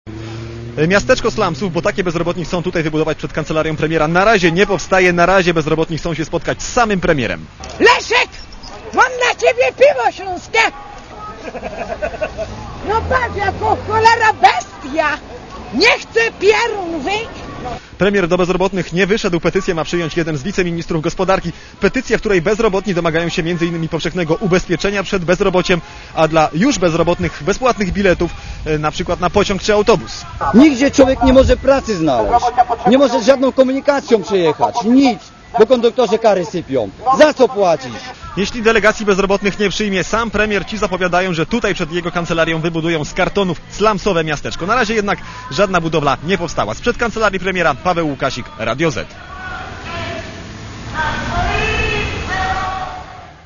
Idąc ul. Nowy Świat, skandują "Pracy i chleba!".
(RadioZet) Źródło: (RadioZet) Komentarz audio Idąc ul.